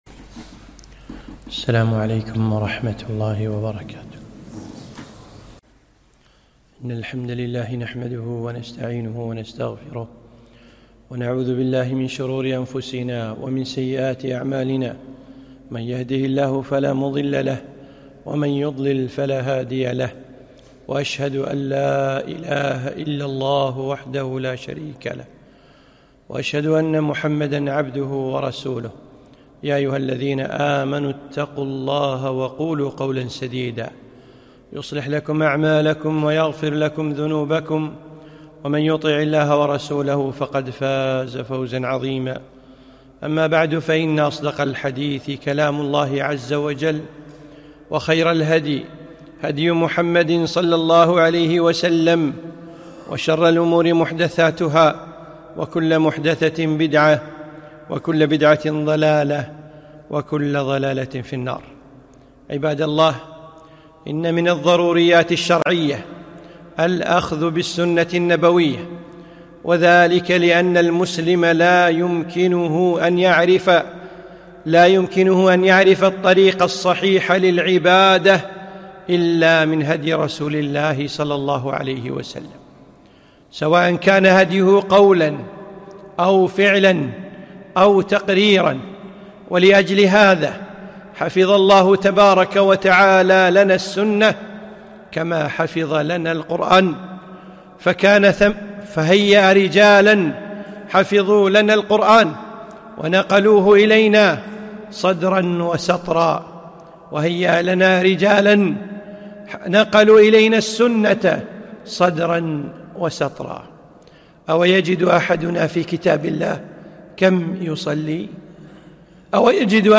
خطبة - ضرورة الأخذ بالسنة